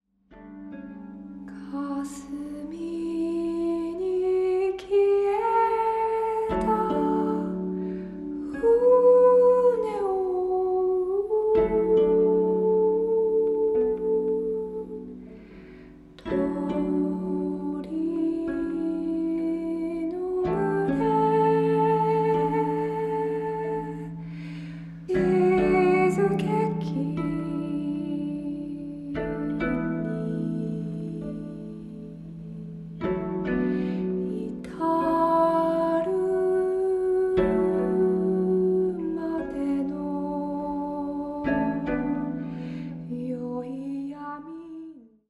十七絃箏と、カリンバ／ハーモニウム／女声による音の綴り。
(17-strings koto)
voice, harmonium